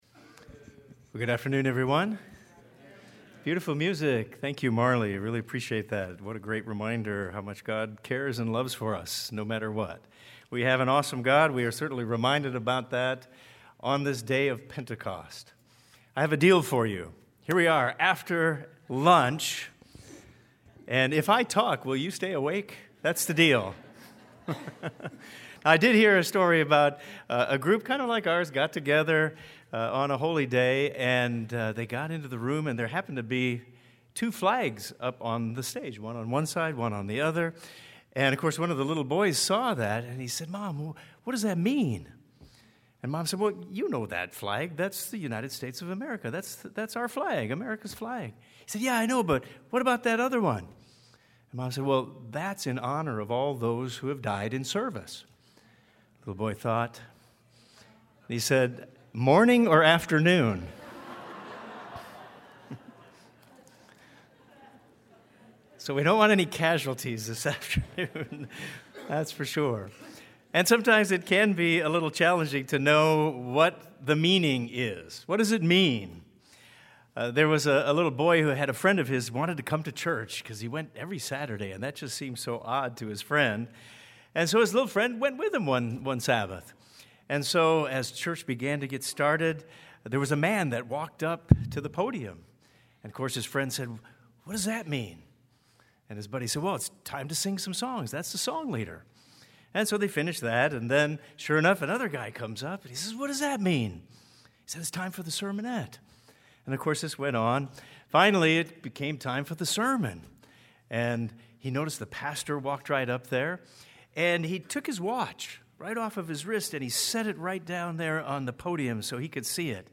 If the Church is to fulfill the mission God gave it, this question must be answered. This sermon will address that question and its meaning and application for every member of the Body of Christ today.